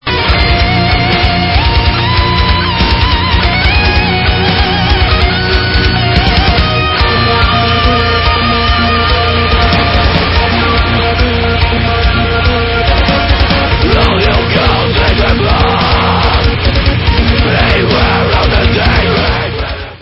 POST-HARDCORE WITH ELECTRONIC APPROACH